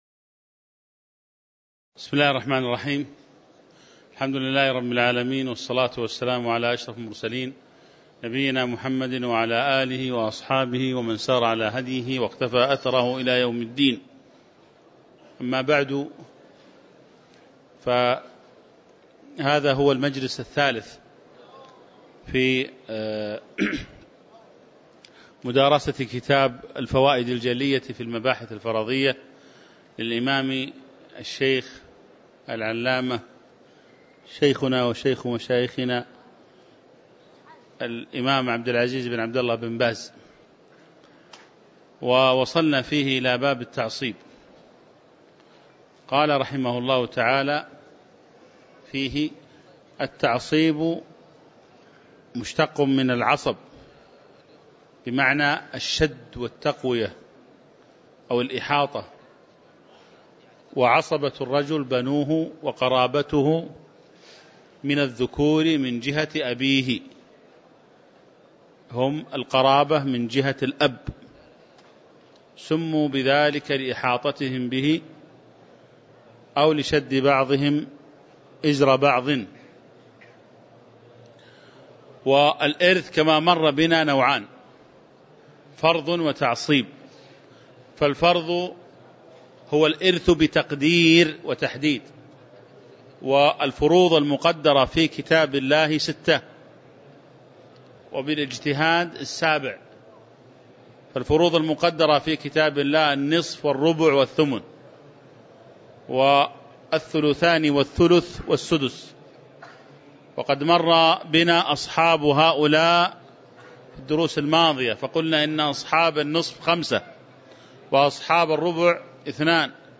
تاريخ النشر ١١ جمادى الأولى ١٤٤١ هـ المكان: المسجد النبوي الشيخ